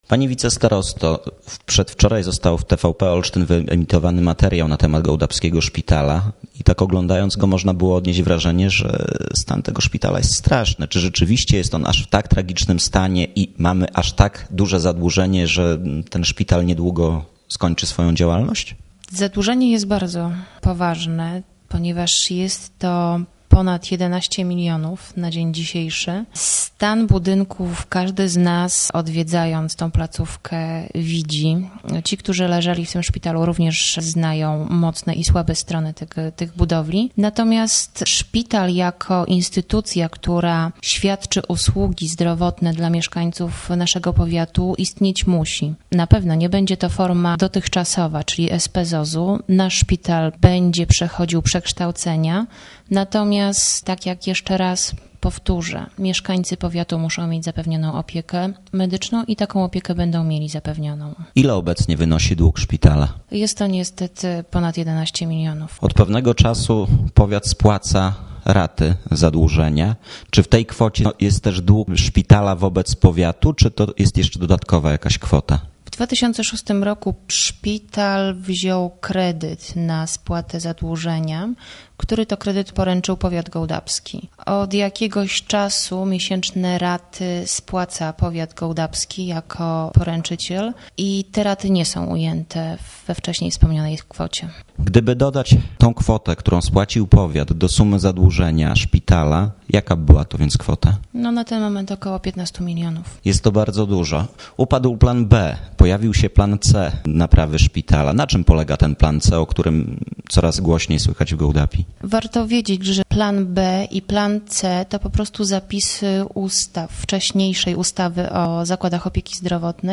rozmowa z wicestarostą gołdapskim Ewą Bogdanowicz-Kordjak